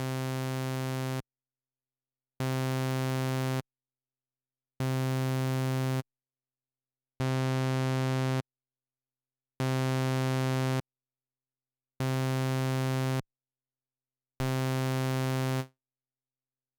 Т.е. даже голая пила уже звучит как-то не так, мерзковато -...
Вот 7 пил из разных синтов. Ничего не нормализовывала. Попыталась подогнать все под примерно -23.7дБ. Почему такое странное число?